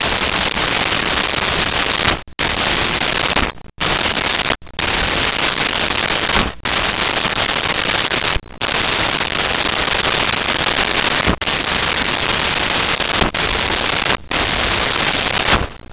Prop Plane Sputters
Prop Plane Sputters.wav